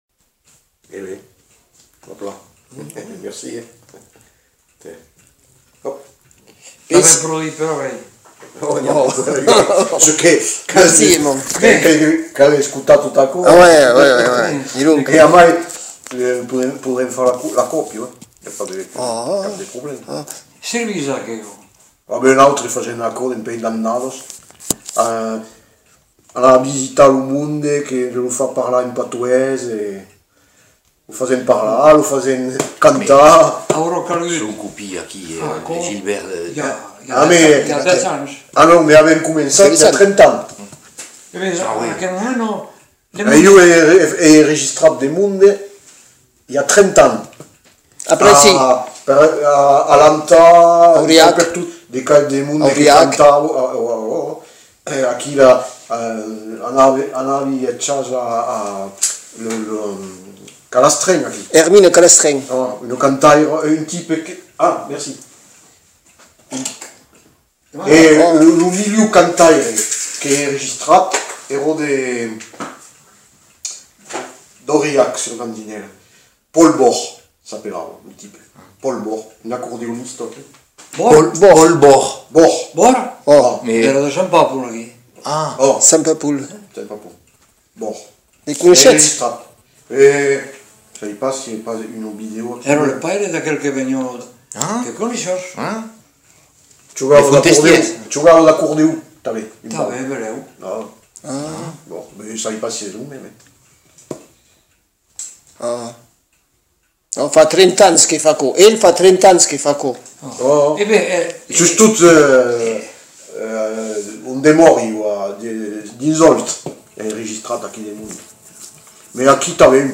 Aire culturelle : Lauragais
Lieu : Le Faget
Genre : témoignage thématique